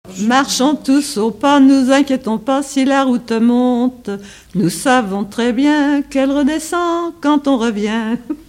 Chants brefs - Conscription
gestuel : à marcher ; enfantine : lettrée d'école ;
Pièce musicale inédite